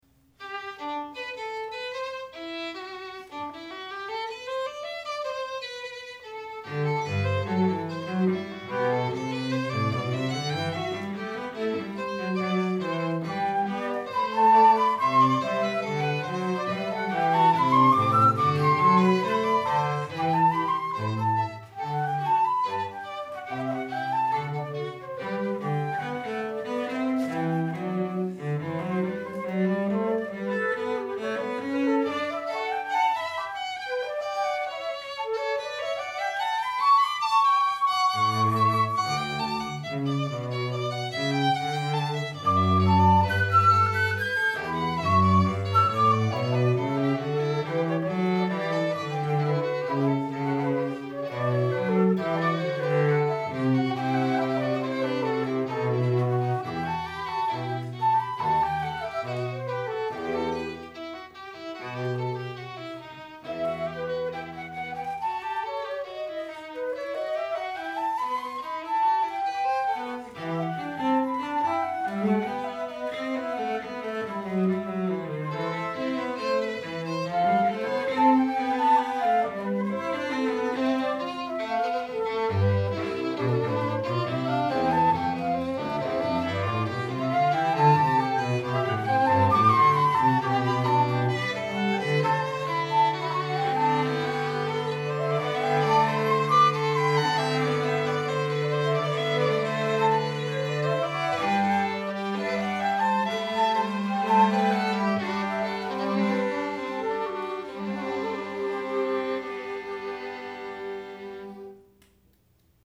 Instrumentation: fl, vln, vc